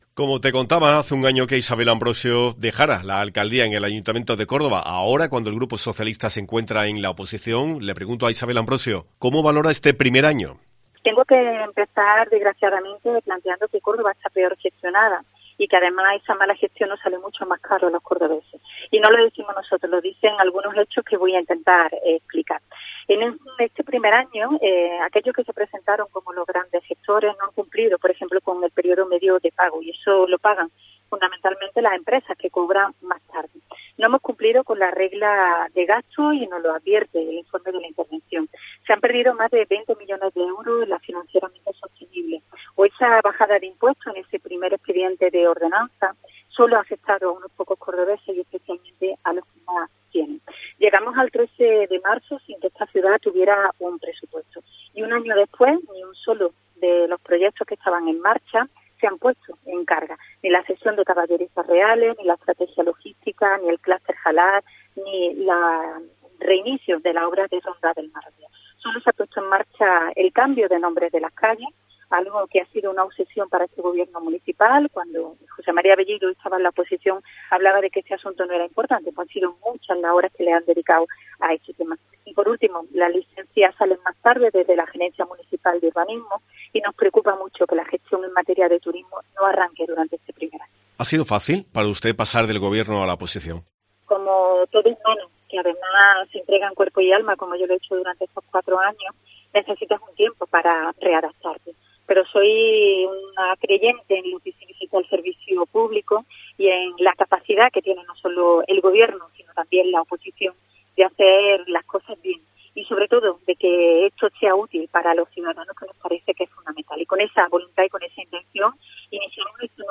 Escucha a Isabel Ambrosio, portavoz de PSOE en el Ayuntamiento de Córdoba